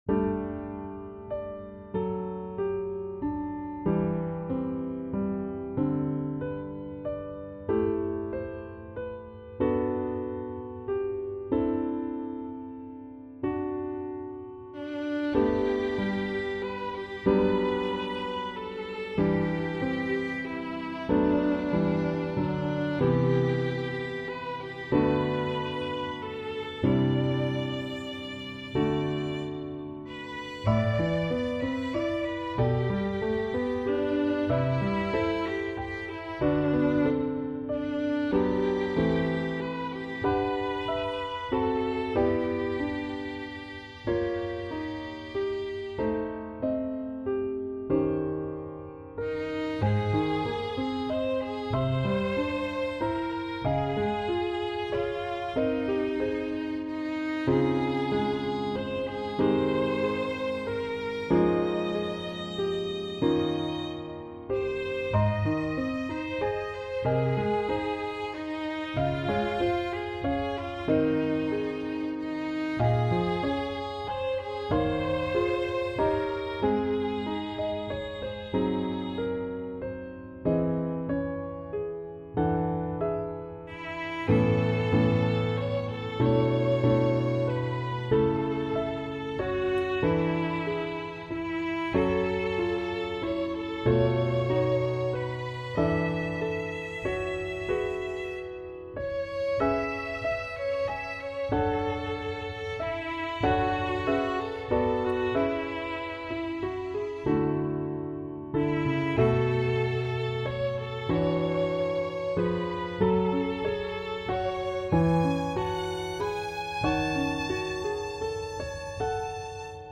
Violin Solo